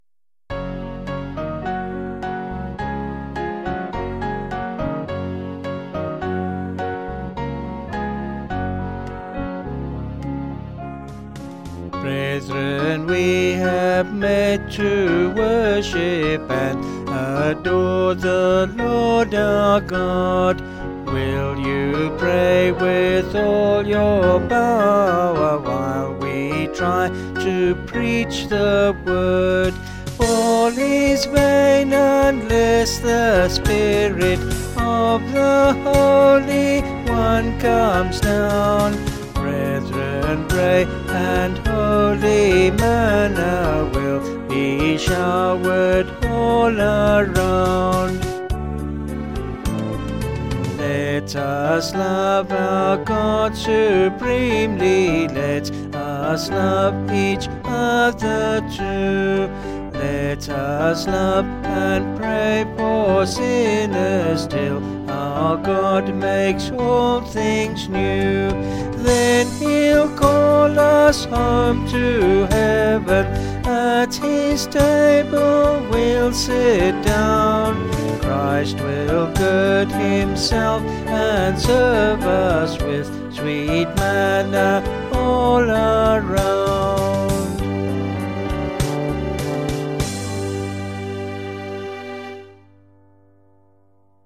Vocals and Band   263.6kb Sung Lyrics